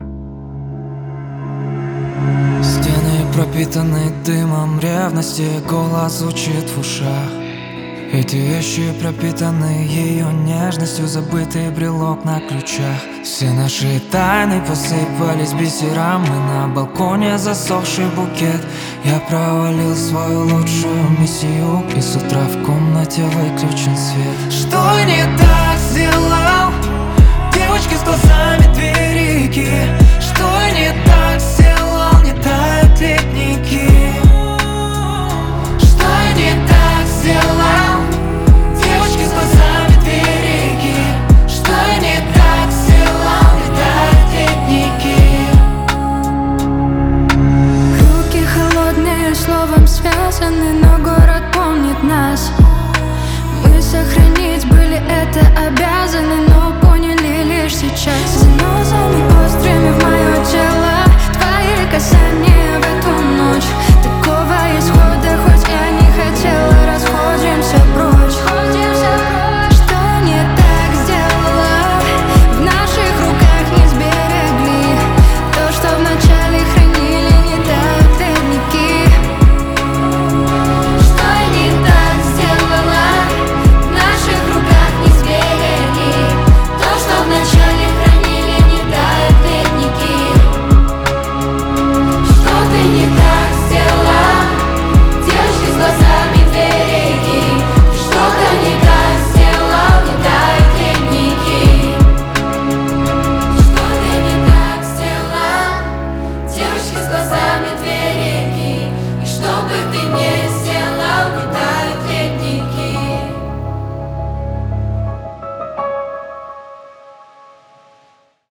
Жанр: Узбекские песни Слушали